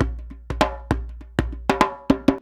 100DJEMB33.wav